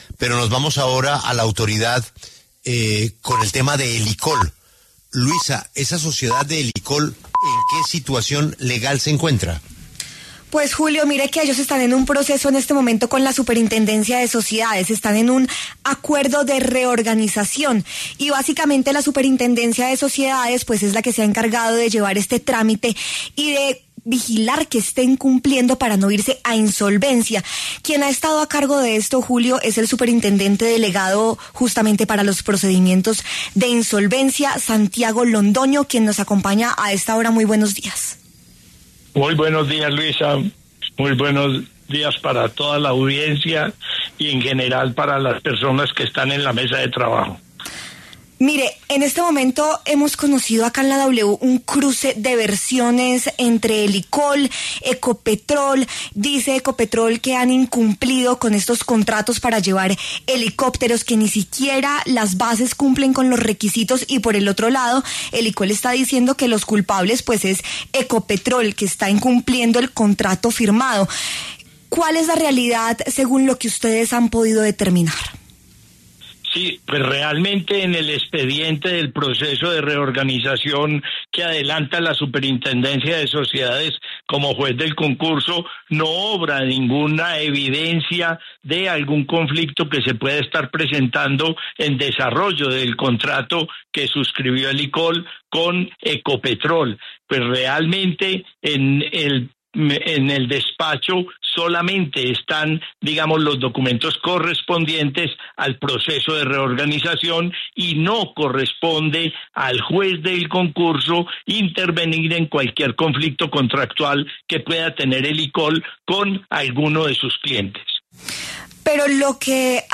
Santiago Londoño, superintendente delegado para los procedimientos de insolvencia, habló en W Radio sobre el cruce de versiones entre Ecopetrol y Helicol, por el presunto incumplimiento del contrato.